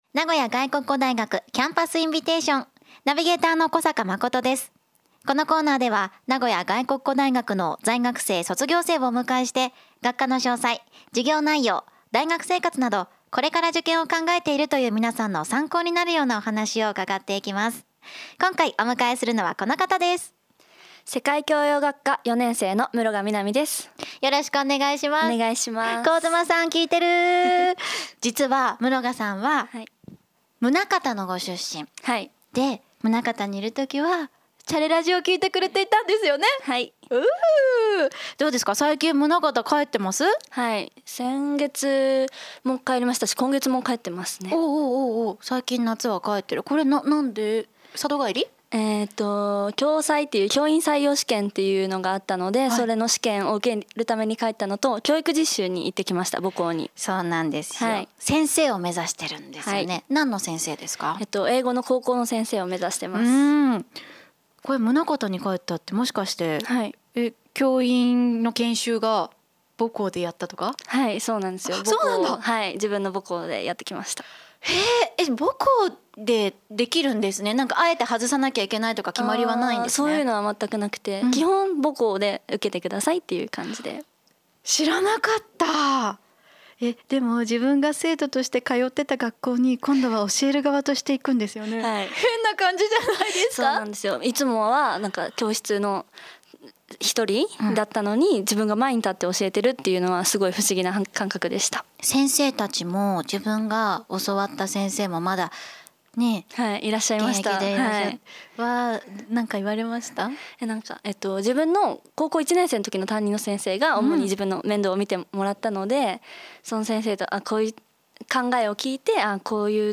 名古屋外国語大学の在学生や卒業生をお迎えして、